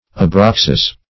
Abraxas \A*brax"as\, n. [A name adopted by the Egyptian Gnostic